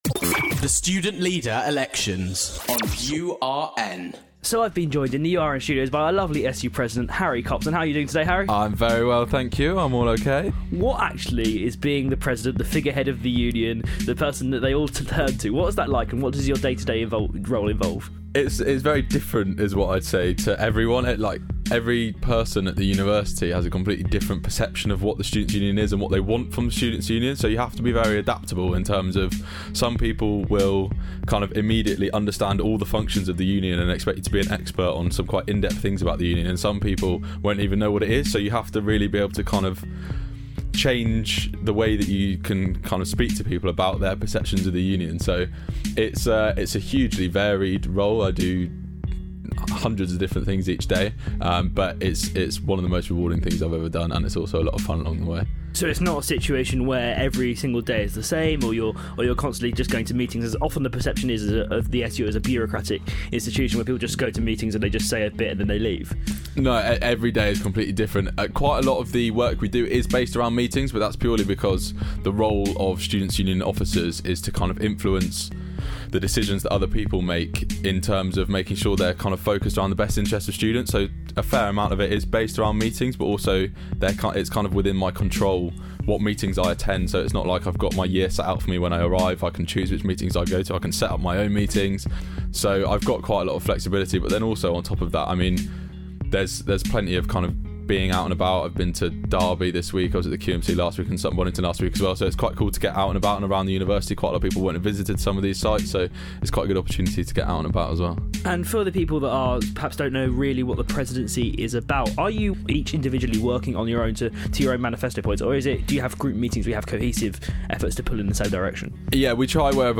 URN Interviews